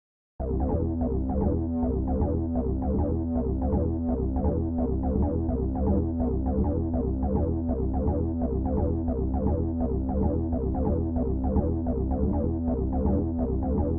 Descarga de Sonidos mp3 Gratis: sintetizador 11.
electronica_6.mp3